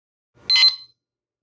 ○予鈴